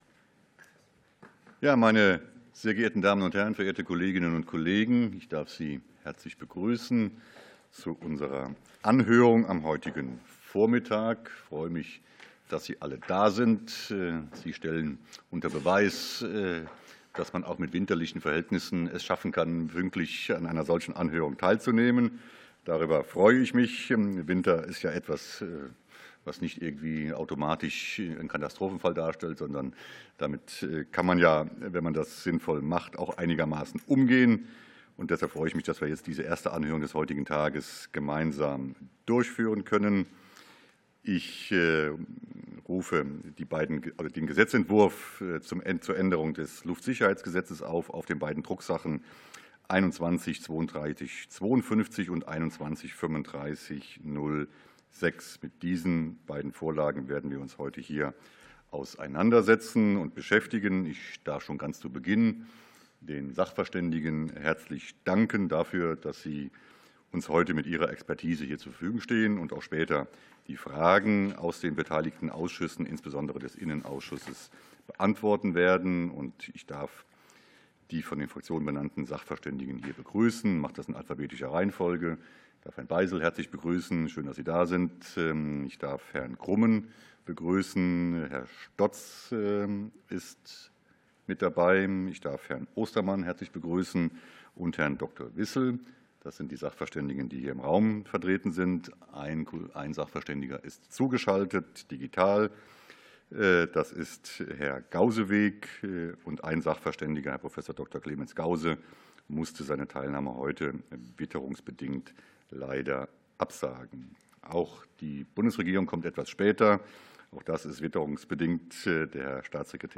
Anhörung des Innenausschusses